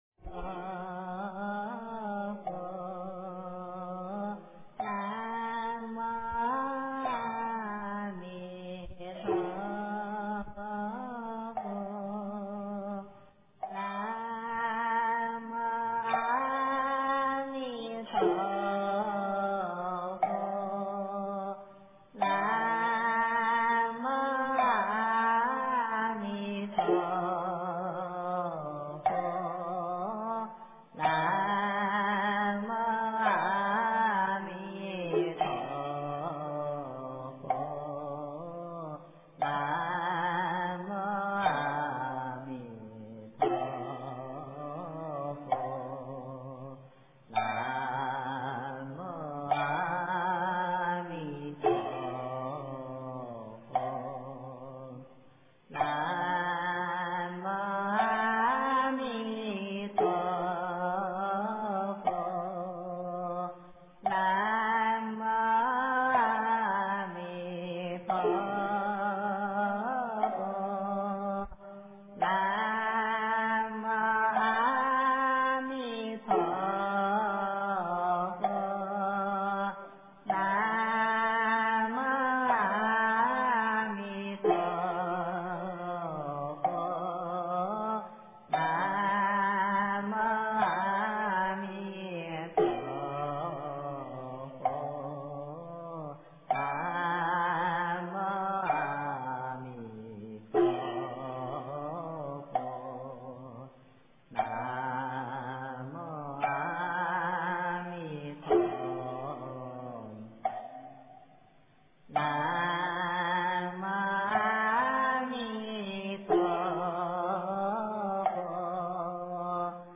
晚课--女众
晚课--女众 经忏 晚课--女众 点我： 标签: 佛音 经忏 佛教音乐 返回列表 上一篇： 南无佛陀--佚名 下一篇： 南无阿弥陀佛--男女6音调 相关文章 宝山偈--群星 宝山偈--群星...